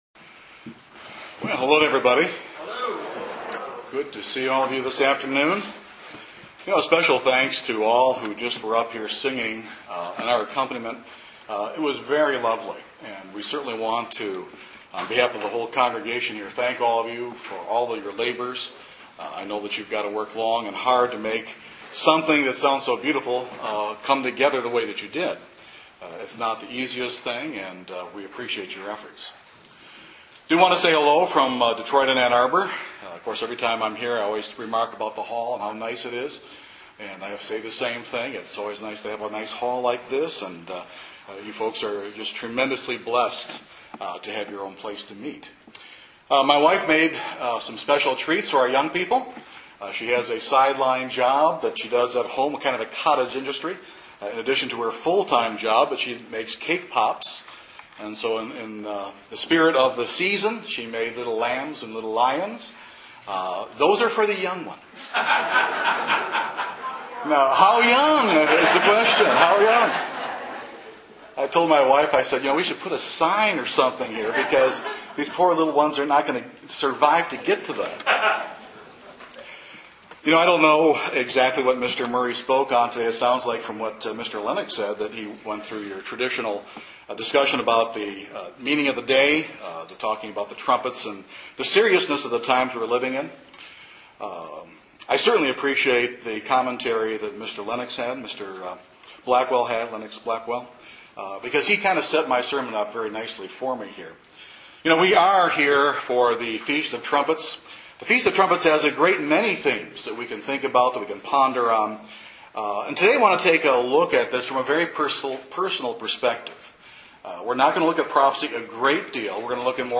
This sermon discusses what makes for a fruitful branch that will be spared and an unfruitful branch that will be burned in the fire.